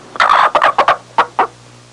Whining Chicken Sound Effect
Download a high-quality whining chicken sound effect.
whining-chicken.mp3